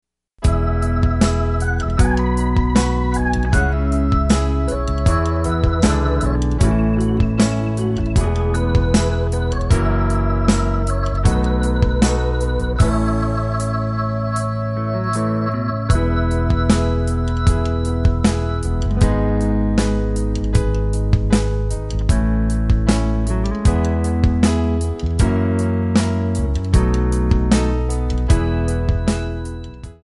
C#
MPEG 1 Layer 3 (Stereo)
Backing track Karaoke
Pop, Rock, 1990s